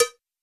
cowbell.wav